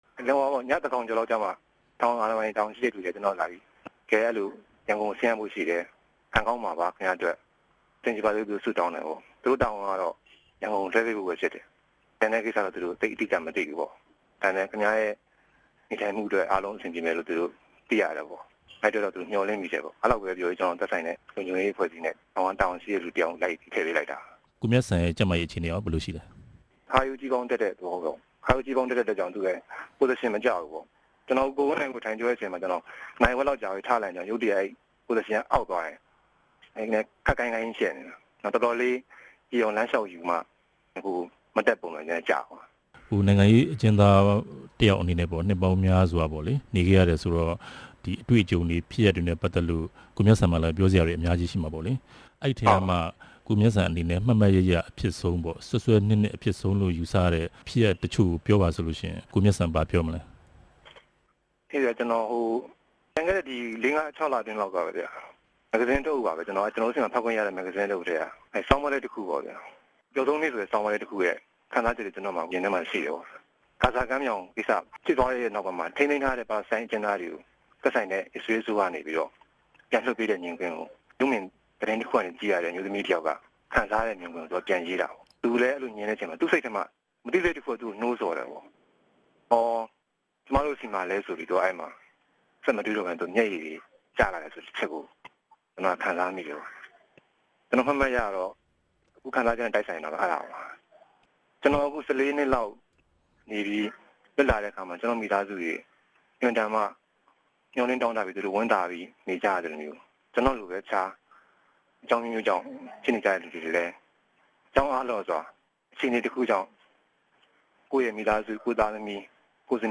Original reporting in Burmese